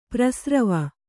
♪ prasrava